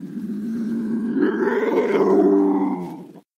sounds / monsters / cat / c2_idle_1.ogg